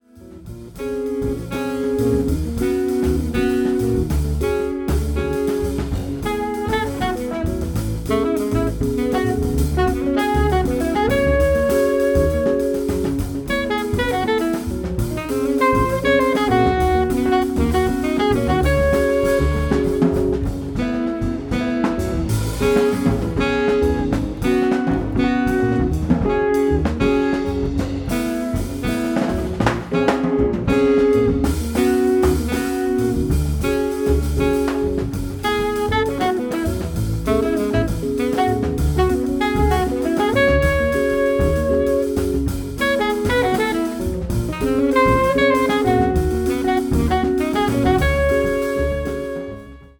clarinet, bass clarinet, alto saxophone
electric guitar
Fender Rhodes, piano, synths
drums
acoustic and electric bass